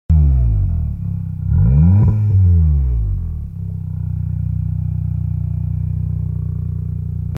New ecs tuning exhaust sounds sound effects free download
New ecs tuning exhaust sounds and looks great!!!